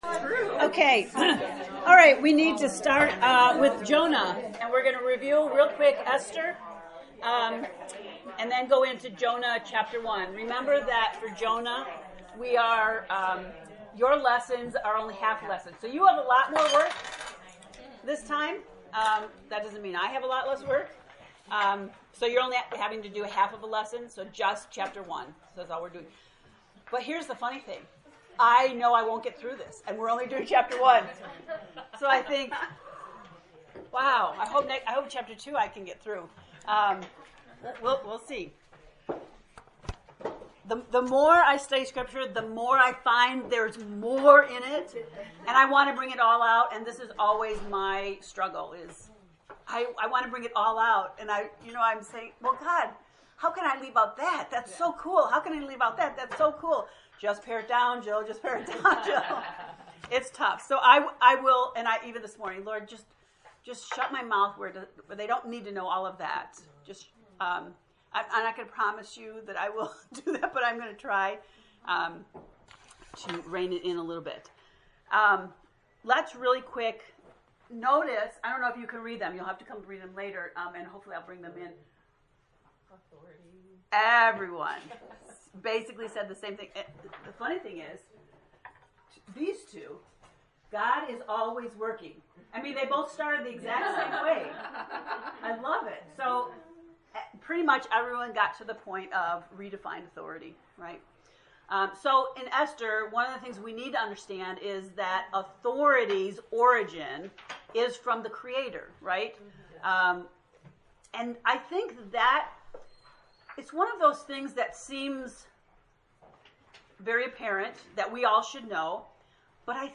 jonah-lect-1a.mp3